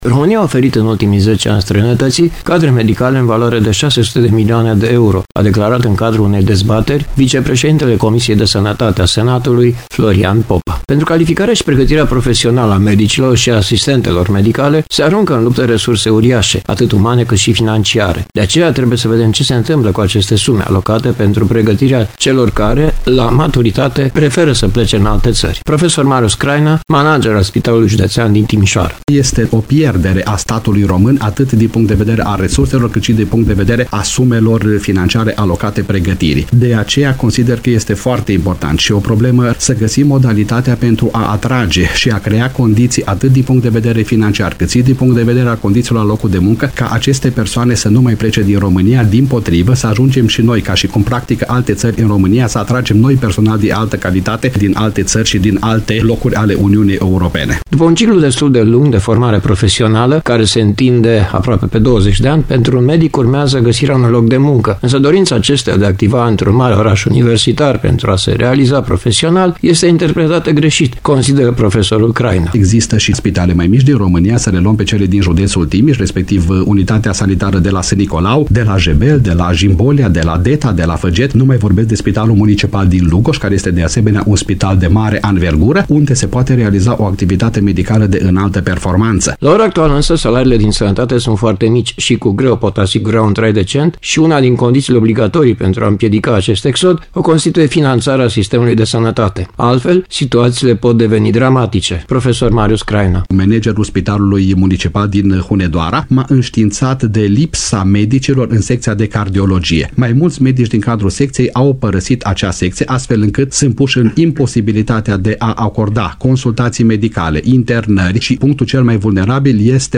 UPDATE sinteza emisiunii